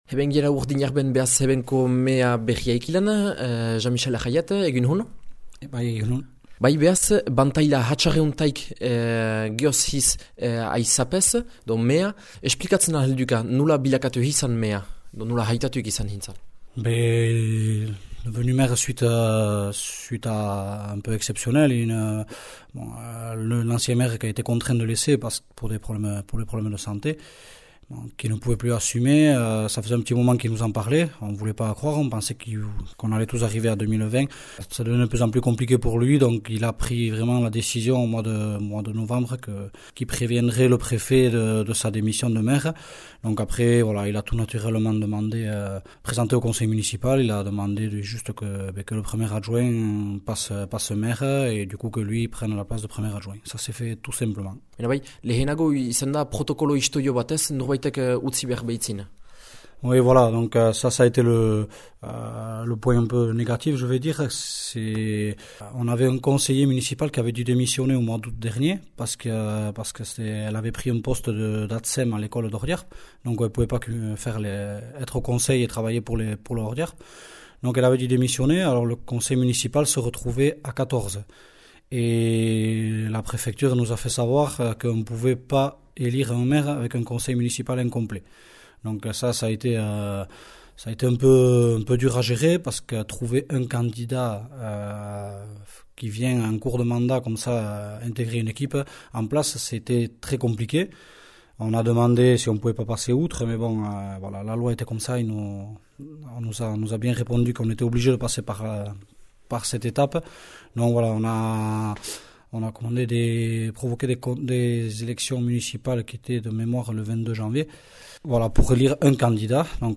Bildüma egin dügü Jean Mixel Arrayet Urdinarbeko aüzapez berriarekin :